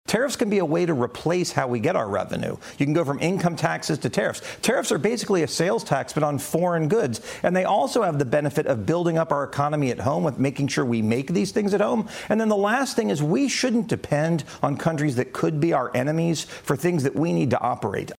Newly Elected Congressman Randy Fine Speaks Out On Tariffs